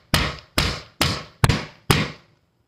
Звуки пульта от телевизора
Стук пульта о тумбочку если не переключает